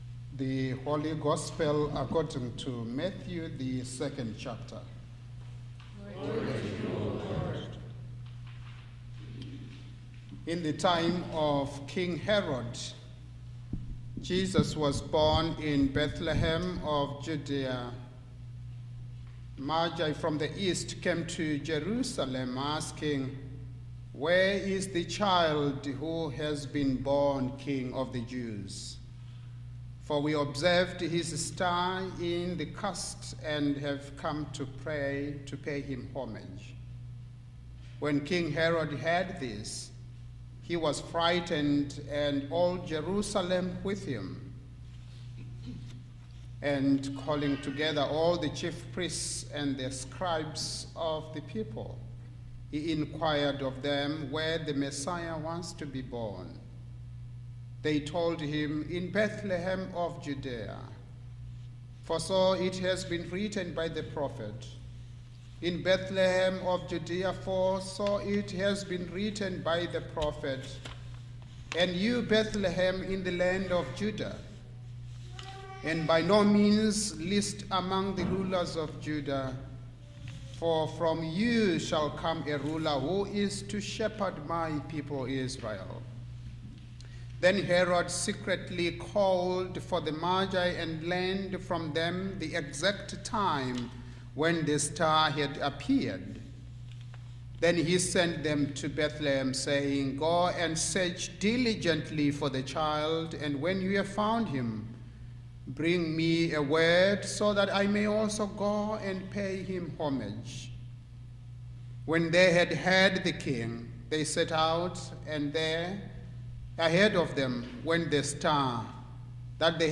Sermon
Sermon for Epiphany 2025